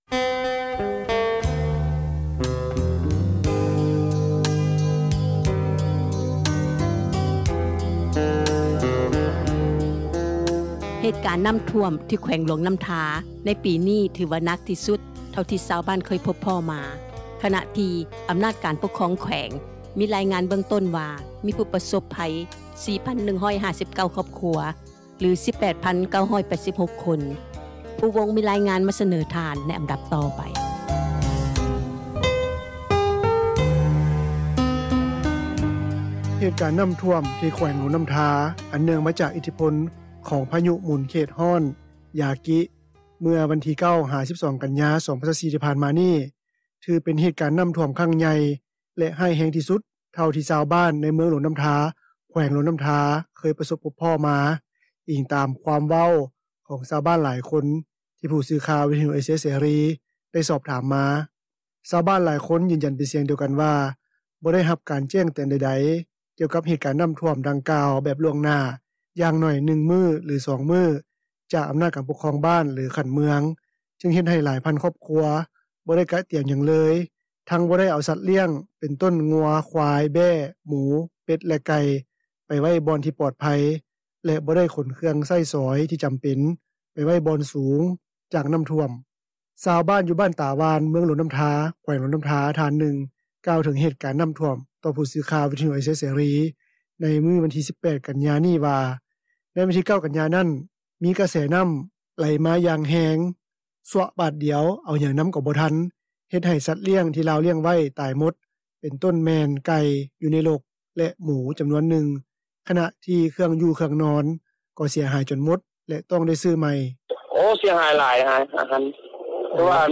ຊາວບ້ານ ຢູ່ບ້ານຕາຫວ່ານ, ເມືອງຫຼວງນ້ຳທາ ແຂວງຫຼວງນ້ຳທາ ທ່ານໜຶ່ງ ກ່າວເຖິງເຫດການນ້ຳຖ້ວມ ຕໍ່ຜູ້ສື່ຂ່າວ ວິທຍຸເອເຊັຽເສຣີ ໃນມື້ວັນທີ 18 ກັນຍາ ນີ້ວ່າ ໃນວັນທີ 9 ກັນຍາ ນັ້ນ, ມີກະແສນ້ຳ ໄຫຼມາຢ່າງແຮງ ຊວົະບາດດຽວ ເອົາຫຍັງນຳກໍບໍ່ທັນ ເຮັດໃຫ້ສັດລ້ຽງ ທີ່ລາວລ້ຽງໄວ້ ຕາຍໝົດ ເປັນຕົ້ນແມ່ນໄກ່ ຢູ່ໃນລົກ ແລະ ໝູ ຈຳນວນໜຶ່ງ. ຂະນະທີ່ເຄື່ອງຢູ່-ເຄື່ອງນອນ ກໍເສັຽຫາຍຈົນໝົດ ແລະ ຕ້ອງໄດ້ຊື້ໃໝ່.